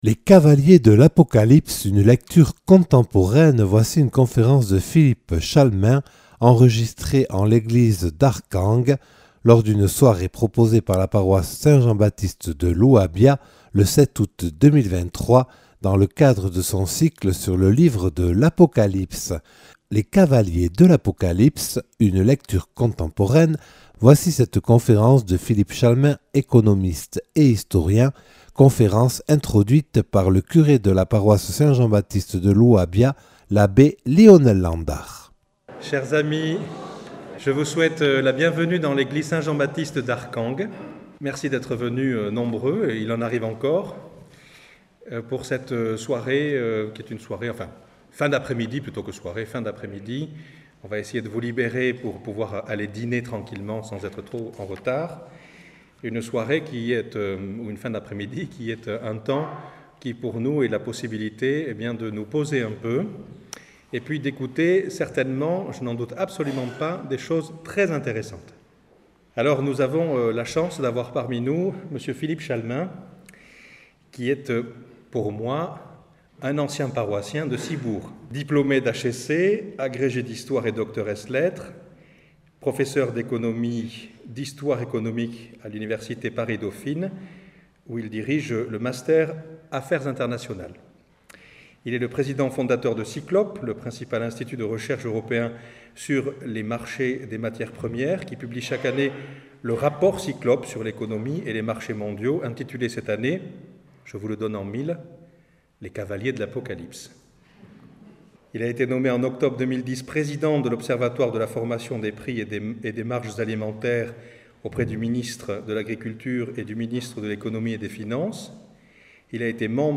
Conférence de Philippe Chalmin, économiste et historien, spécialiste mondial du marché des matières premières, professeur à Dauphine, président de l’observatoire de la formation des prix et des marges des produits alimentaires, fondateur de Cyclope (marchés mondiaux) et d’Ulysse (club d’économistes français).
Enregistrée le 7/08/2023 lors d’une soirée proposée par la Paroisse Saint Jean-Baptiste de l’Uhabia – Arcangues dans le cadre de son cycle sur le livre de l’Apocalypse.